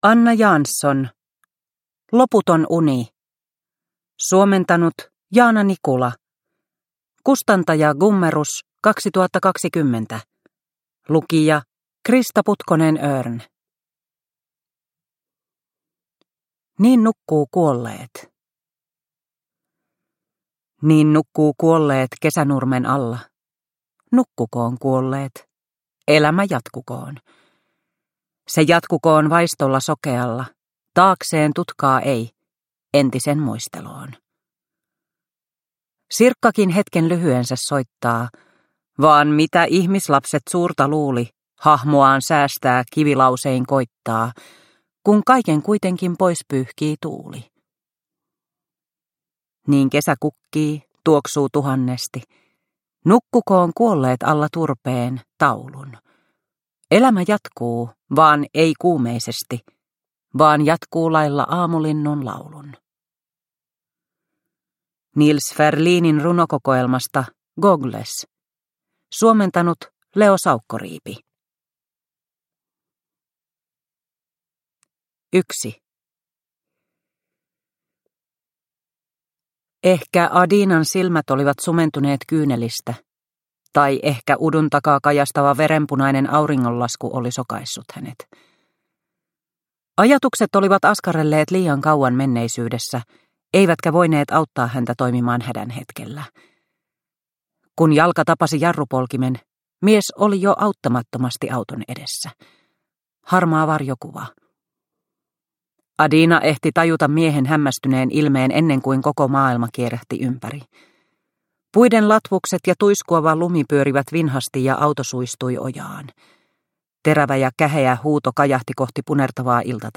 Loputon uni – Ljudbok – Laddas ner